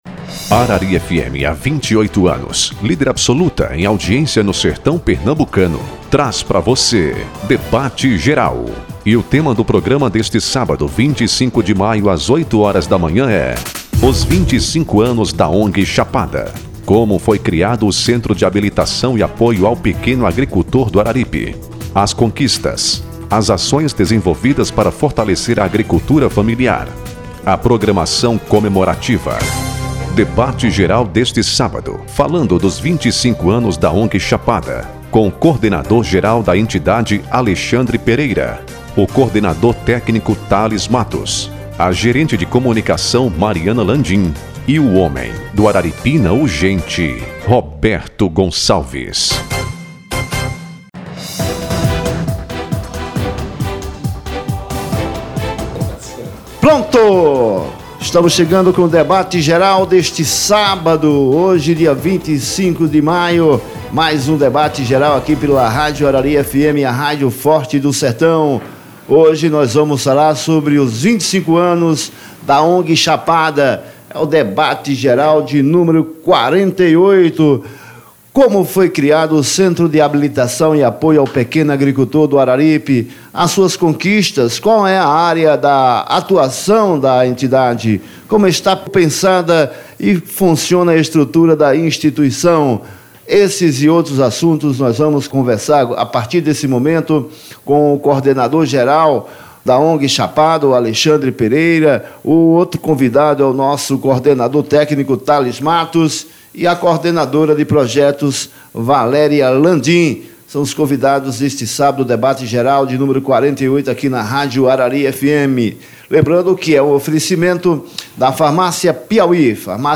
O jornalismo da Rádio Arari FM 90,3 trouxe na manhã deste sábado 25 maio, o 48º programa Debate Geral.